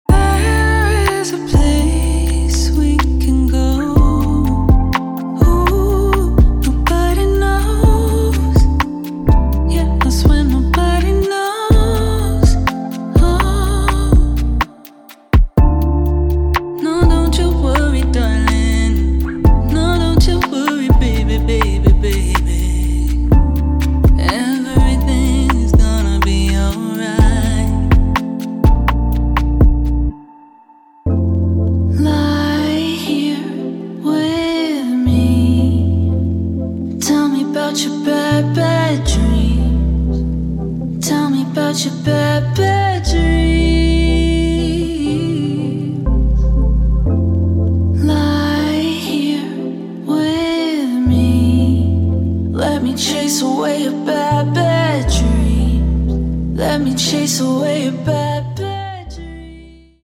Recorded in LA